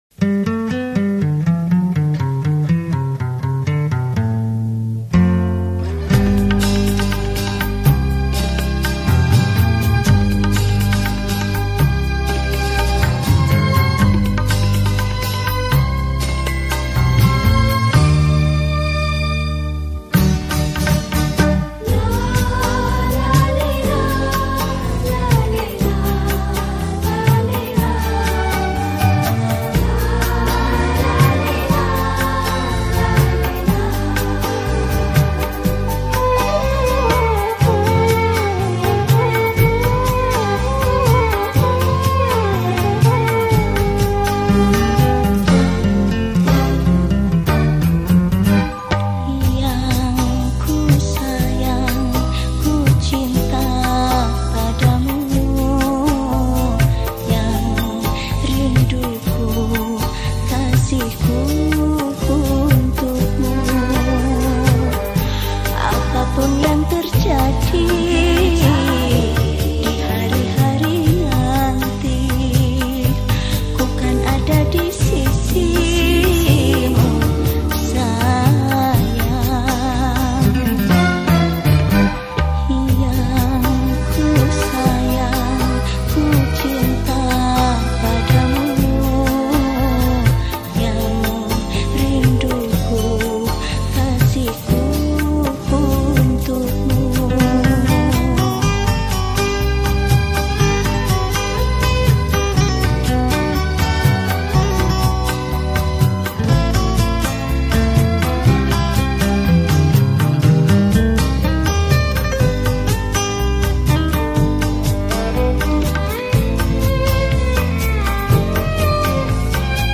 Professi                                    : Penyanyi
Genre Musik                            : Dangdut Original